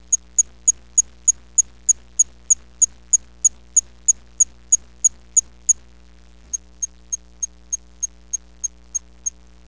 カネタタキ
木の上で生活し、♂だけが、「チッ チッ チッ」 っと羽を立てて鳴きます。
威嚇の時の音は、目覚まし時計の音に似ています。
♂同士が威嚇して鳴いているところ
kanetataki.wav